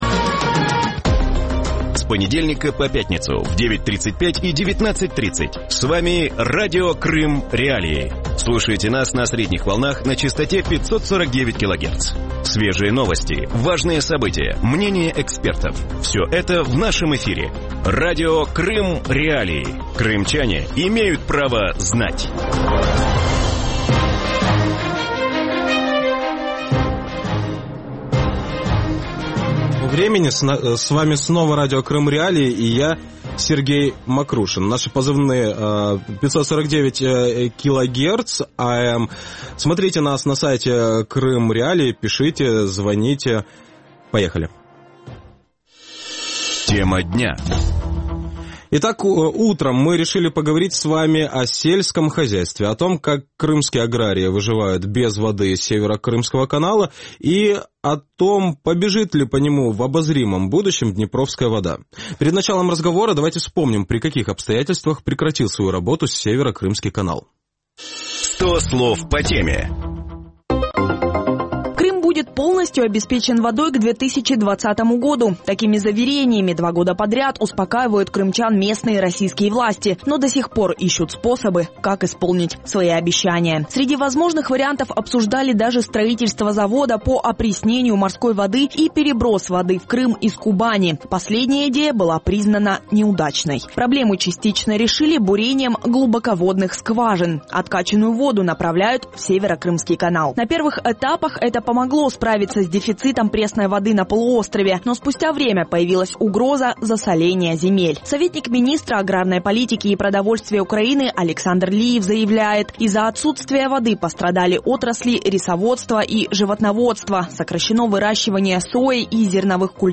Утром на Радио Крым.Реалии беседуют о том, как крымские аграрии выживают без воды из Северо-Крымского канала.